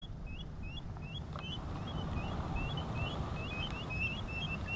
Ostrero Austral (Haematopus leucopodus)
Nombre en inglés: Magellanic Oystercatcher
Fase de la vida: Adulto
Localidad o área protegida: Camarones
Condición: Silvestre
Certeza: Observada, Vocalización Grabada
ostrero-austral.mp3